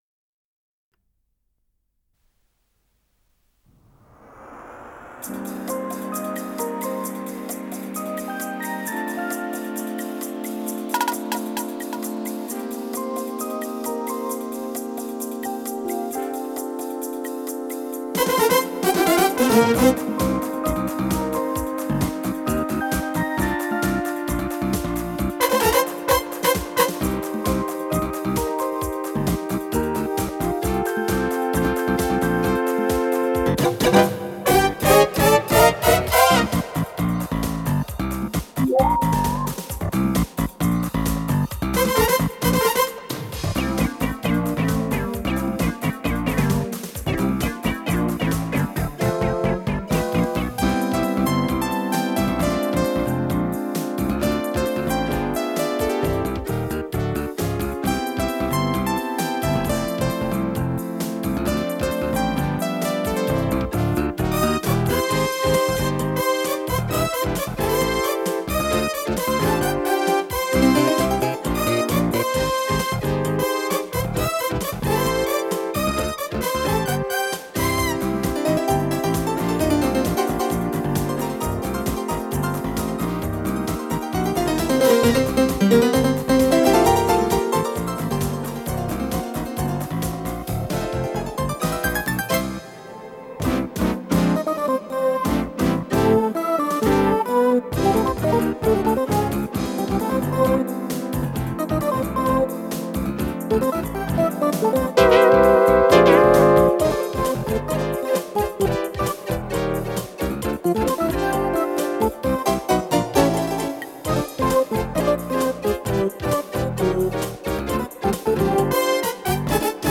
с профессиональной магнитной ленты
Скорость ленты38 см/с